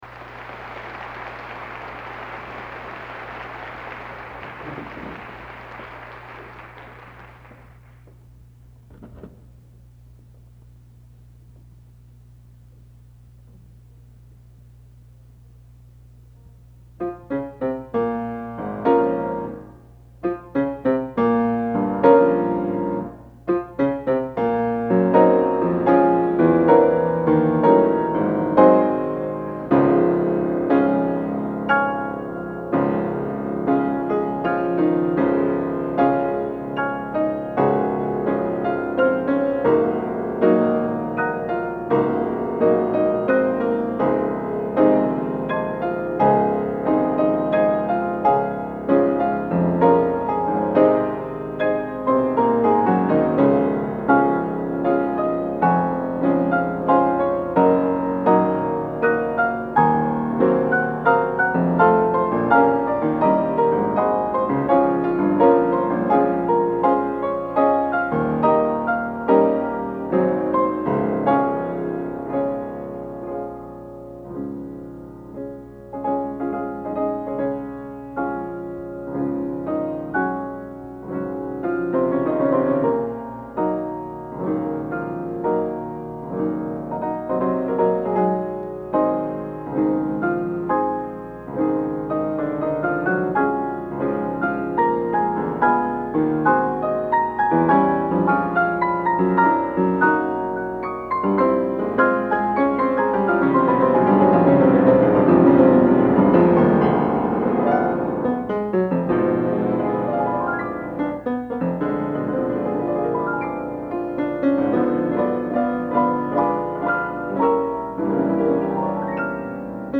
Collection: Performance at Loeb, 1962
Location: West Lafayette, Indiana
Genre: Instrumental Romantic Period | Type: